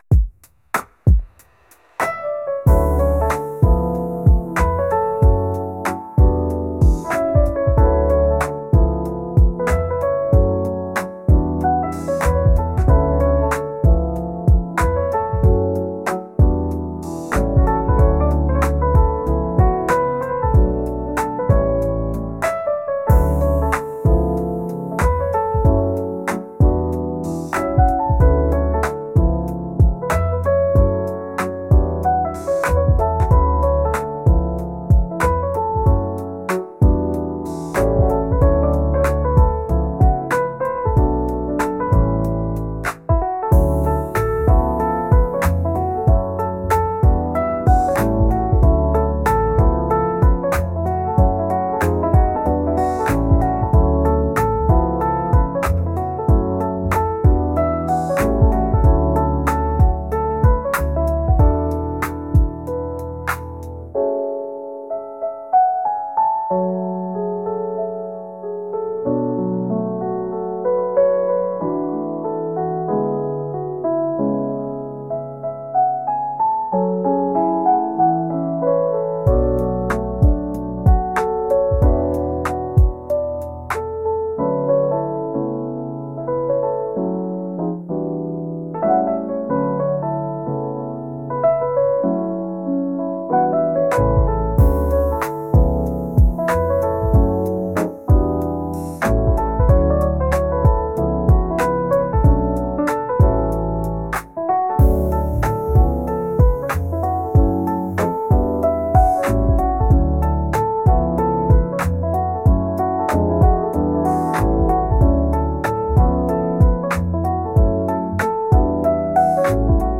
R&B おしゃれ ピアノ